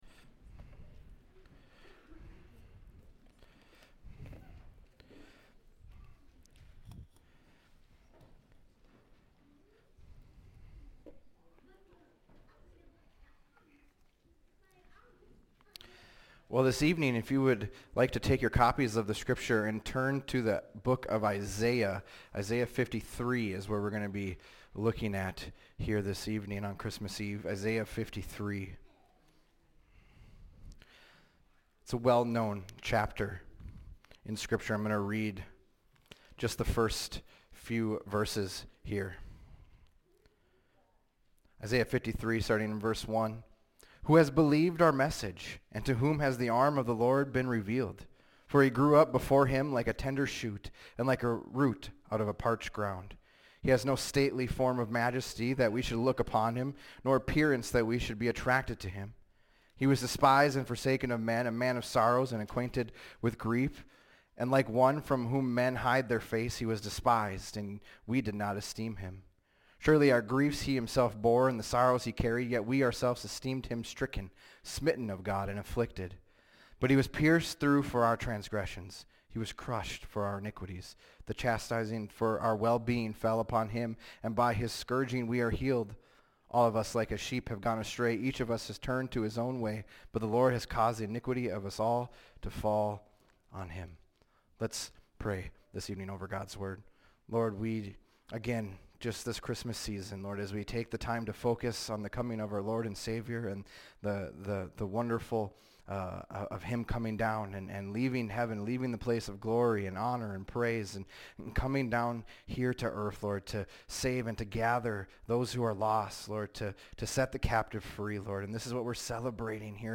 fbc_sermon_122425.mp3